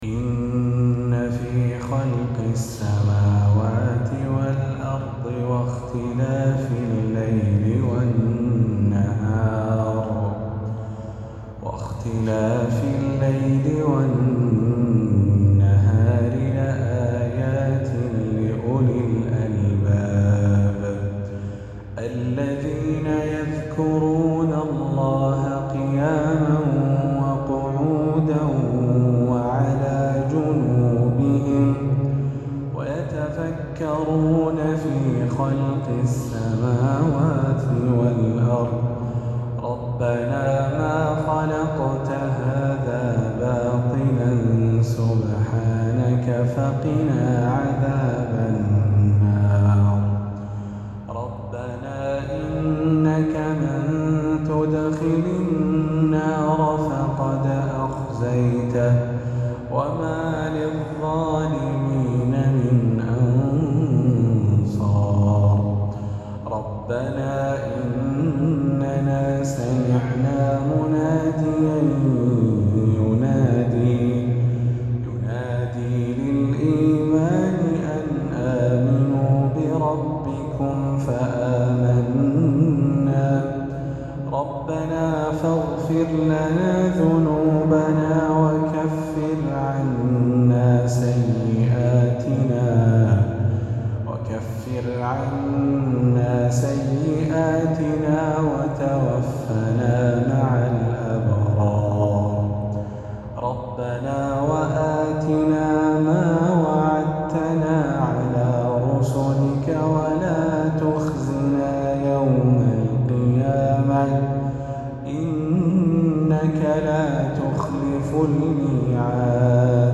فجرية هادئة